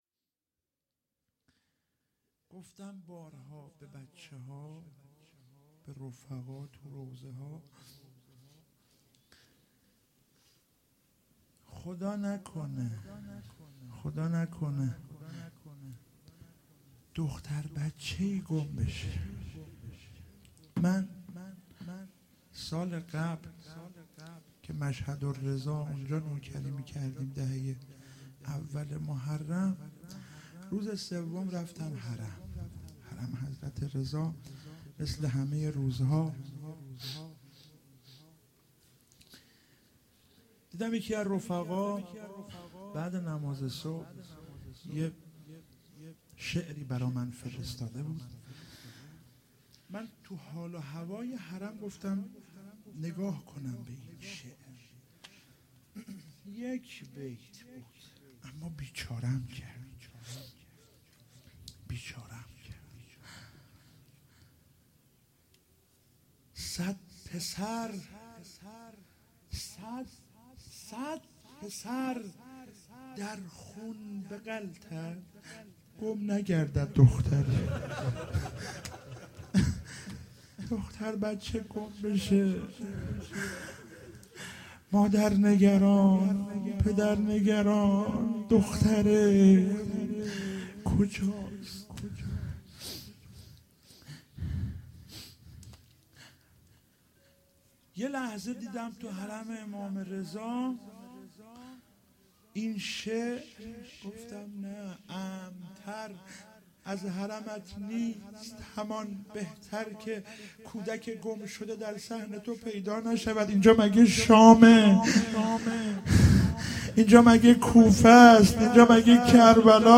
شب سوم محرم97 - روضه - بخش دوم